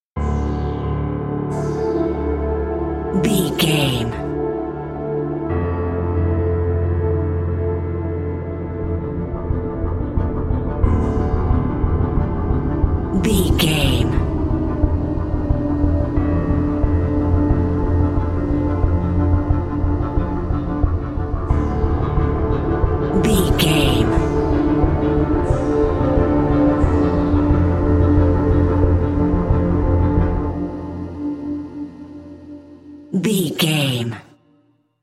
Thriller
Aeolian/Minor
Slow
piano
synthesiser